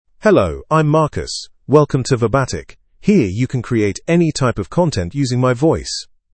Marcus — Male English (United Kingdom) AI Voice | TTS, Voice Cloning & Video | Verbatik AI
MaleEnglish (United Kingdom)
Marcus is a male AI voice for English (United Kingdom).
Voice sample
Marcus delivers clear pronunciation with authentic United Kingdom English intonation, making your content sound professionally produced.